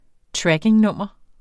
Udtale [ ˈtɹageŋ- ]